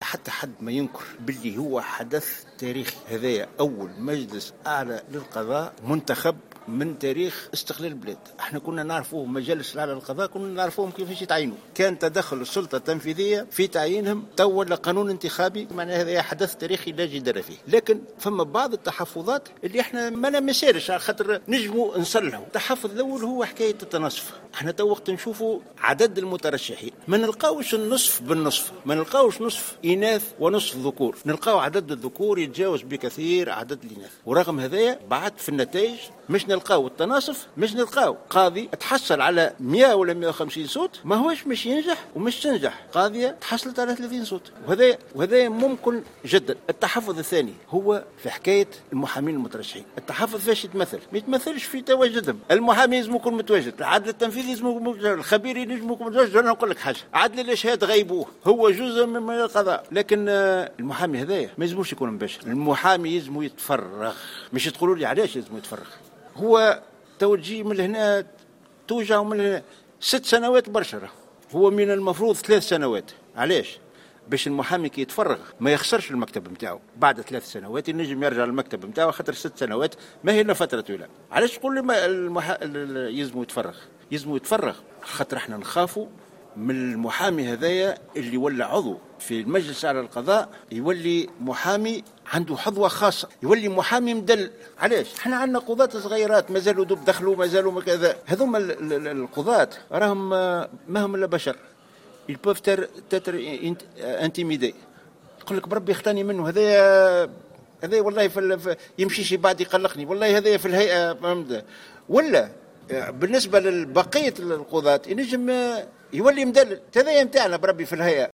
وقال جميل عياد في تصريح لـ "الجوهرة أف أم"، إنه من غير المعقول أن يمارس المحامي نشاطه وأن يرافع أمام قاض وهو في نفس الوقت عضو بالمجلس الأعلى للقضاء حيث ان هذا الأمر قد يؤدي إلى تمتع هذا المحامي بحظوة خاصة لدى القاضي، مشيرا في المقابل إلى إشكالية عدم ممارسة المحامي لنشاطه لمدة 6 سنوات وهي المدة الانتخابية المحددة للمجلس الأعلى للقضاء.